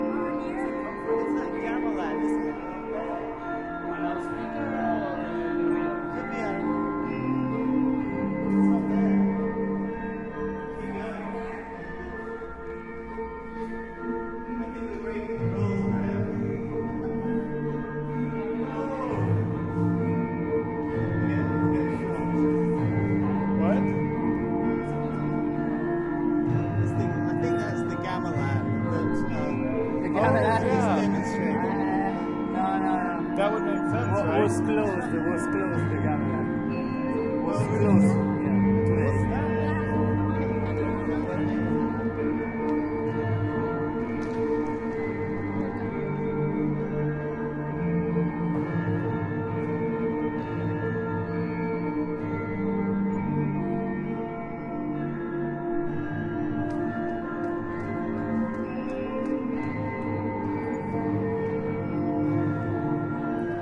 描述：Casa da Musica的机器人加麦兰在排练。讲话.
Tag: smc2009 卡萨-DA-MUSICA 加麦兰 波尔图 机器人